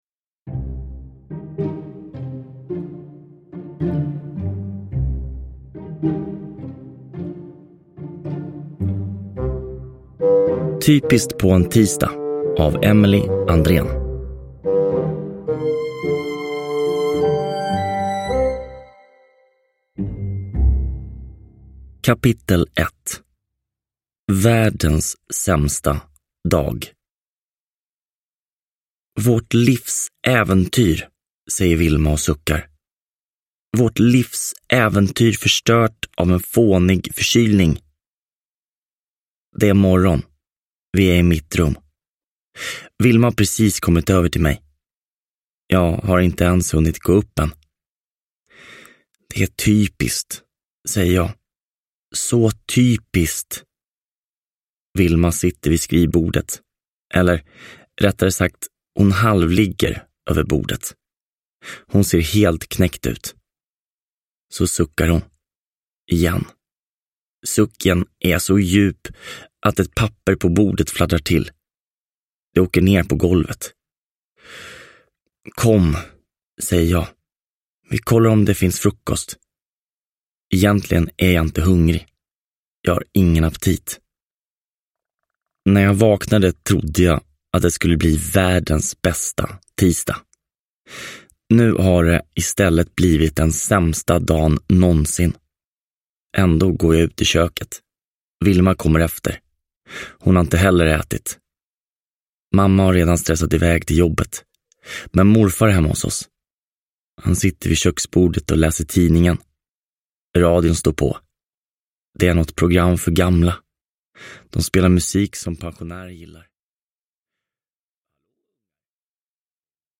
Typiskt på en tisdag – Ljudbok